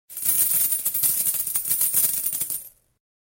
SFX游戏环境音灌注的金币素材音效下载